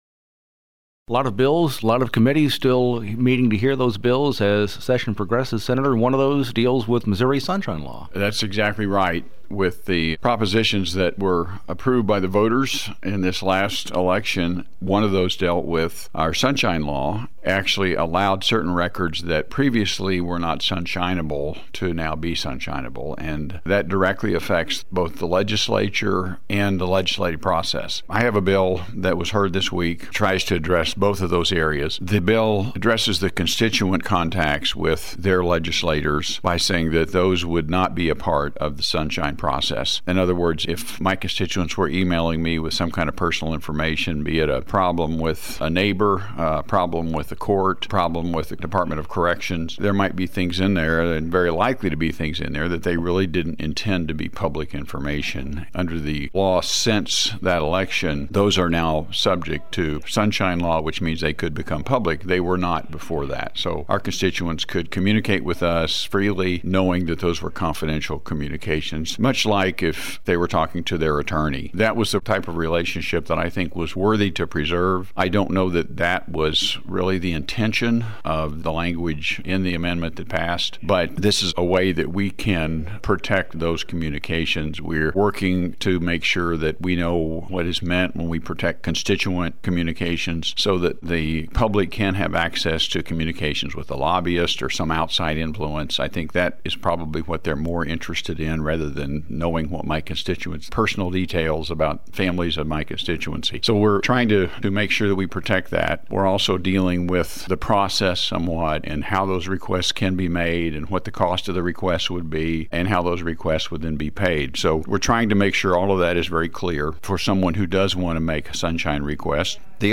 JEFFERSON CITY — State Sen. Ed Emery, R-Lamar, discusses Senate Bill 132, a measure that seeks to authorize closure of certain constituent and legislative records of members of the Missouri General Assembly; Senate Bill 9 and Senate Joint Resolution 2, both of which would — upon voter approval — require the Missouri Senate to try all impeachments; and Senate Bill 7, legislation that would modify provisions of civil procedure regarding joinder and venue.